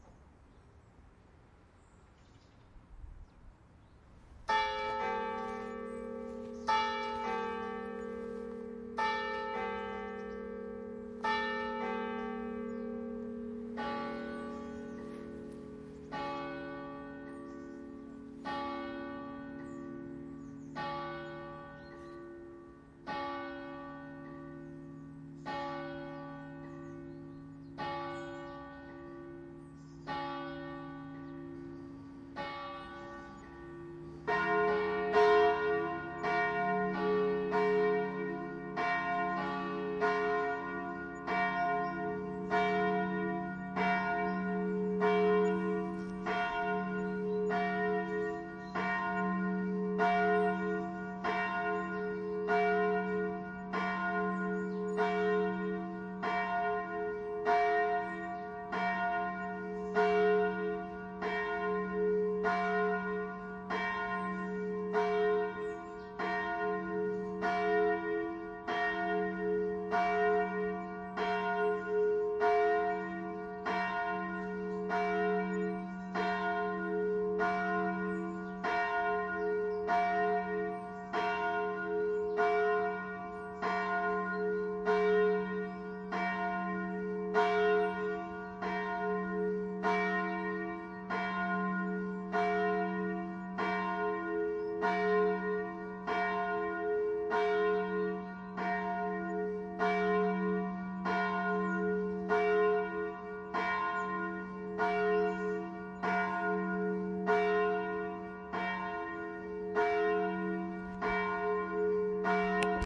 描述：h1缩放。钟声响了好几分钟。开始赢了一个响，然后赢了另一个。几分钟后停止录制。
Tag: 教堂 敲打 教堂钟声